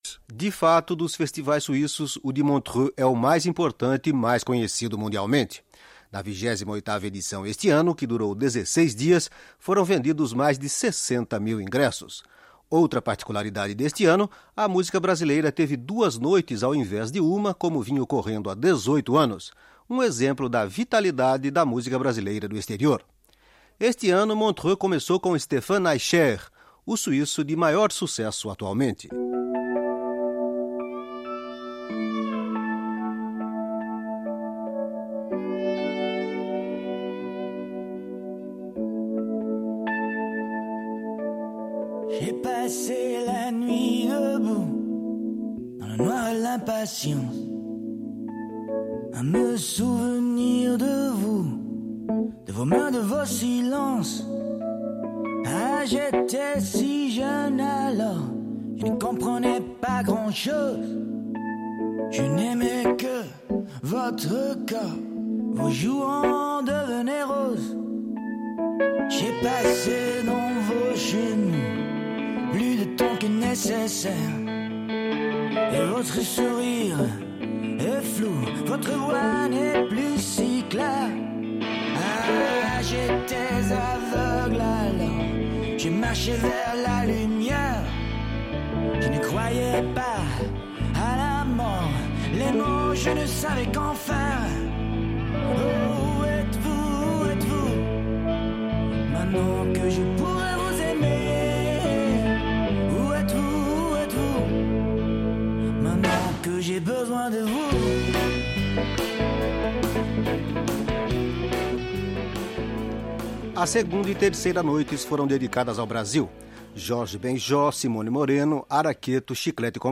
A reportagem que você vai ouvir a seguir é de 1994, com Ney Matogrosso e Daniela Mercury.